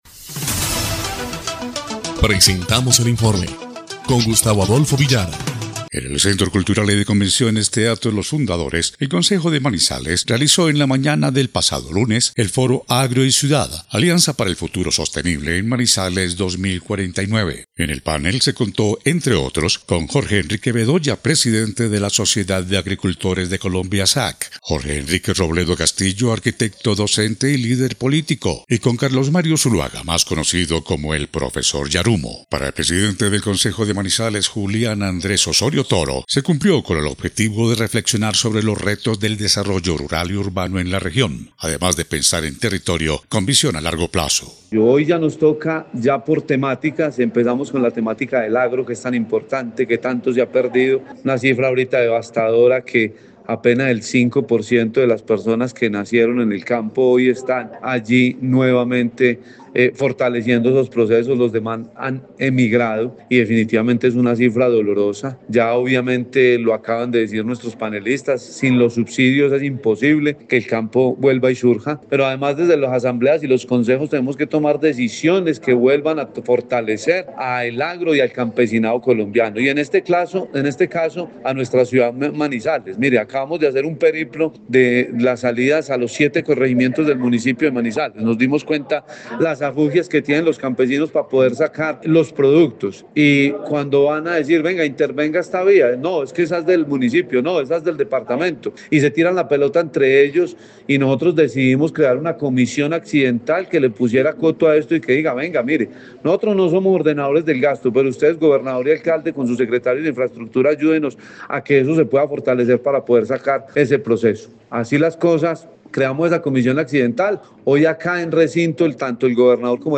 EL INFORME 3° Clip de Noticias del 29 de julio de 2025